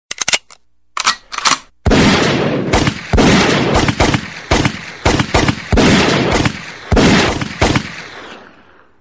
Nada Dering Orderan Grab, Gojek… Lucu
Kategori: Nada dering
Dengan suara guns sound yang unik, nada dering ini akan memberikan kesan berbeda setiap kali kamu menerima orderan.
nada-dering-orderan-grab-gojek-lucu-id-www_tiengdong_com.mp3